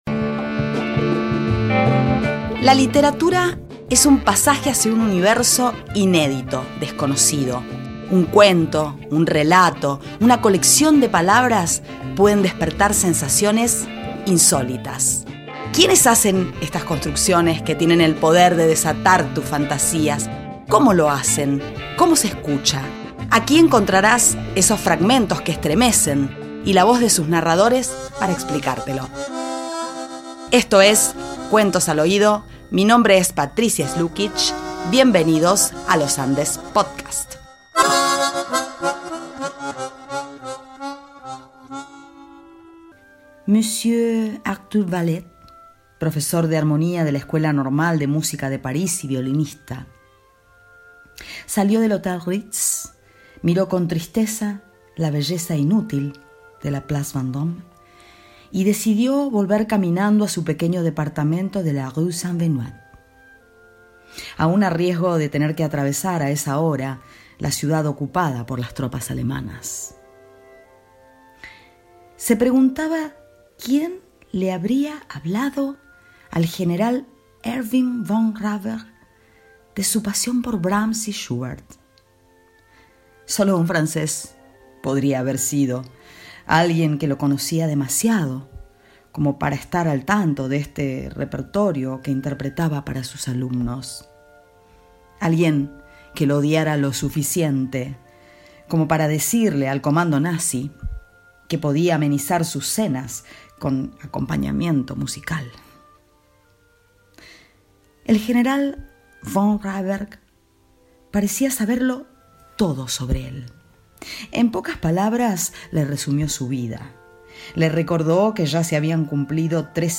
Narramos un fragmento del cuento "La venganza del músico", que forma parte de "El tropiedo del tiempo" (Eduardo Álvarez Tuñón).